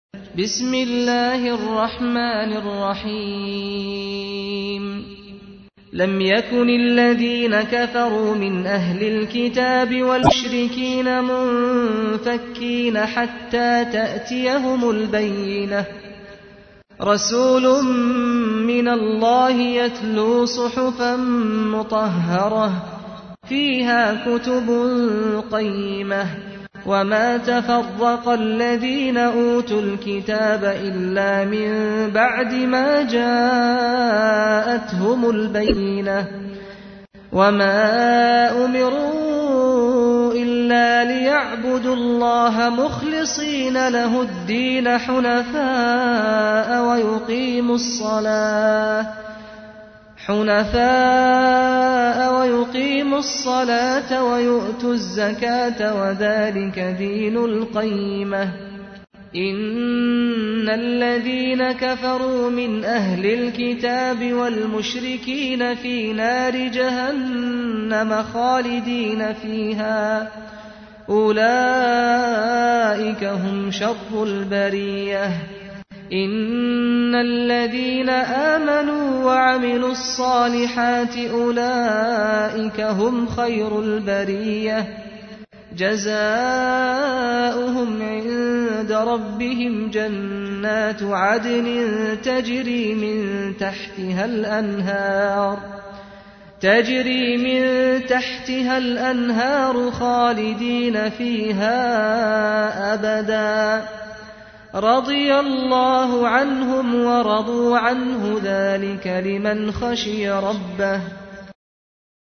تحميل : 98. سورة البينة / القارئ سعد الغامدي / القرآن الكريم / موقع يا حسين